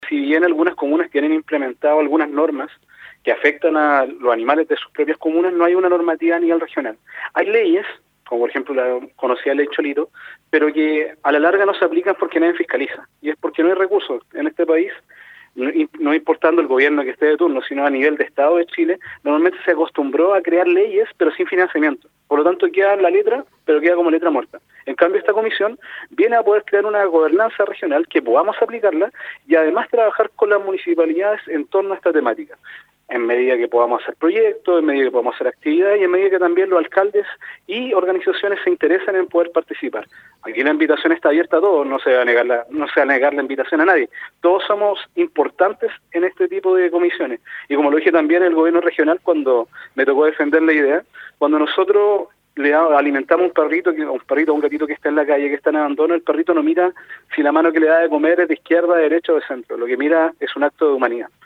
En entrevista con Radio UdeC, destacó el carácter pionero a nivel nacional que tendrá esta iniciativa.